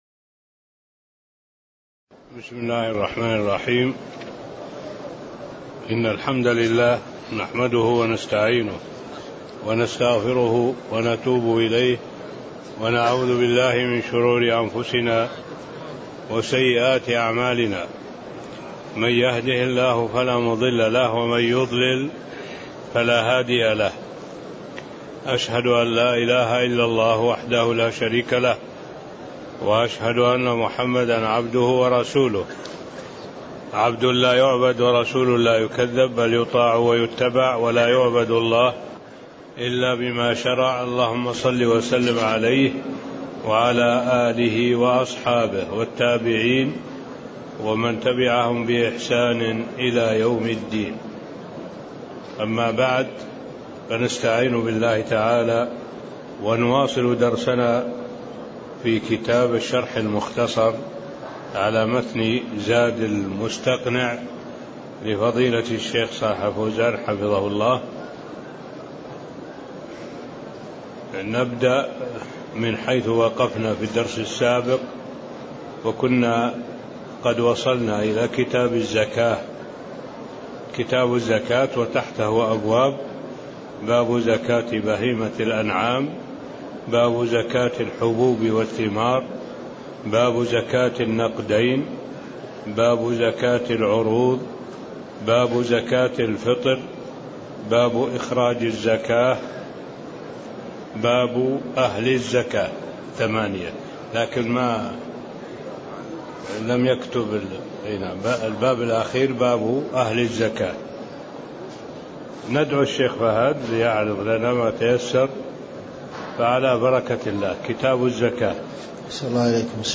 تاريخ النشر ١٥ رجب ١٤٣٤ هـ المكان: المسجد النبوي الشيخ